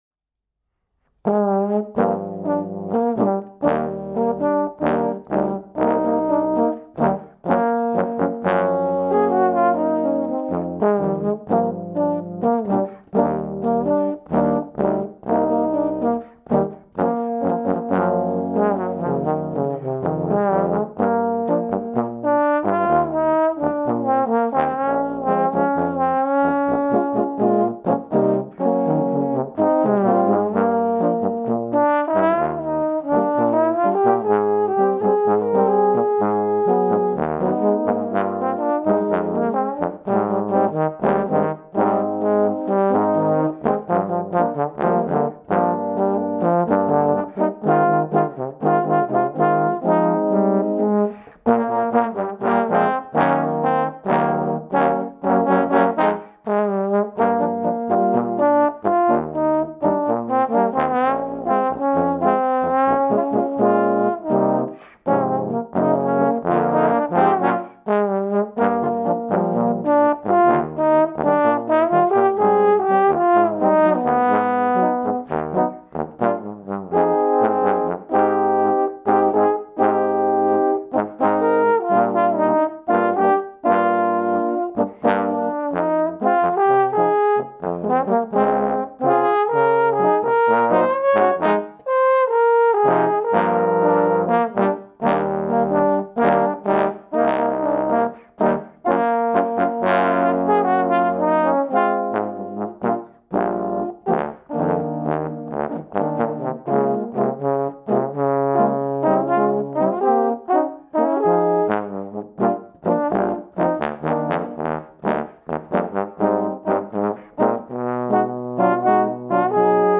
for Trombone Quartet
An original Latin-style number for trombones.